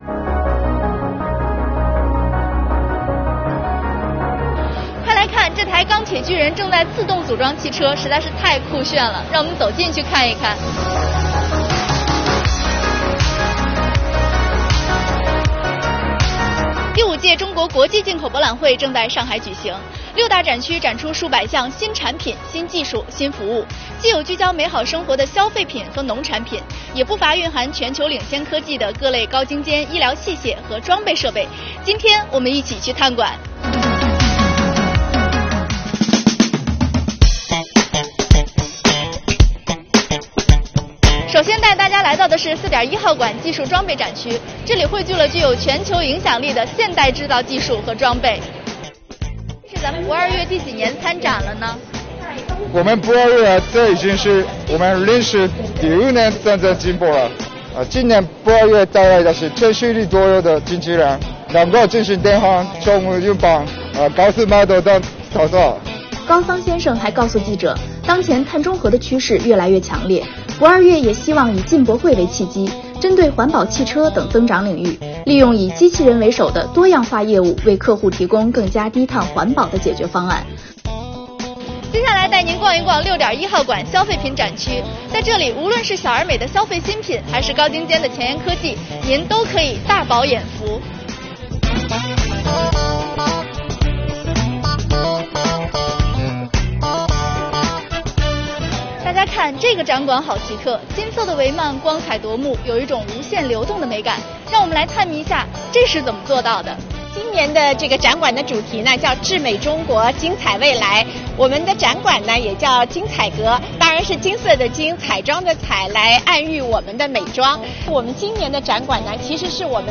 出镜记者